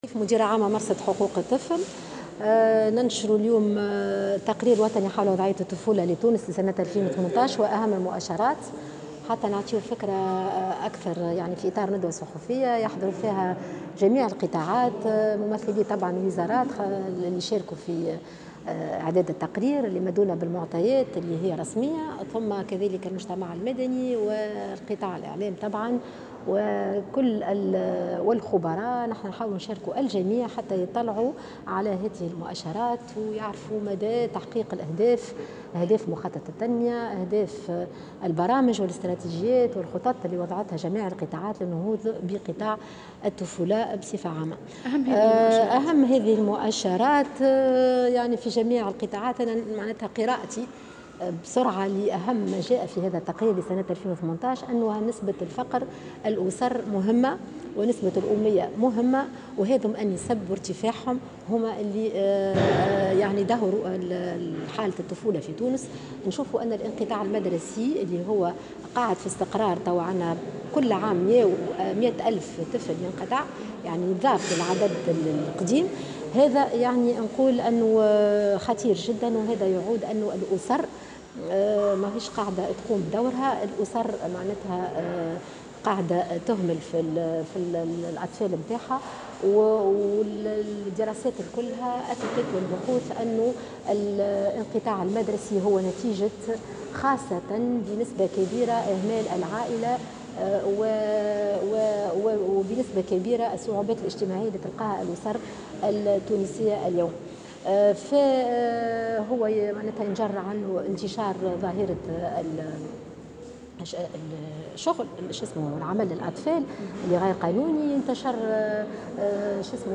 نشر مرصد الإعلام والتكوين والتوثيق والدراسات حول حماية حقوق الطفل، خلال ندوة صحفية اليوم، التقرير الوطني حول وضع الطفولة في تونس لسنة 2018.